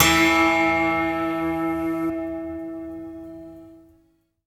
Es hat eine leichte Rauschfahne drauf, die aber mitten im Sample abbricht. Klingt so, als seien da zwei unterschiedlich lange Samples gelayert, um den Attack zu betonen. Könnte auch ein oktaviertes Layer desselben Sounds sein...